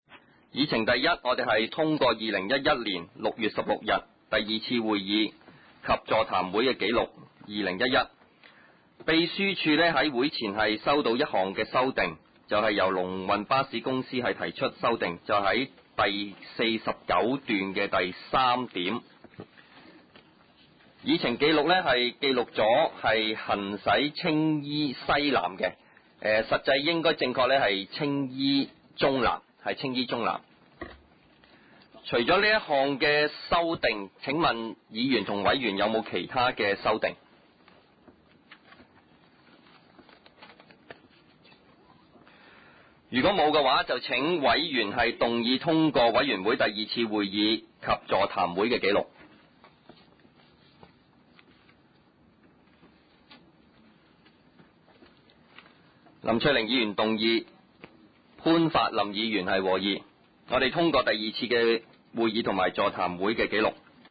第三次會議(二零一一)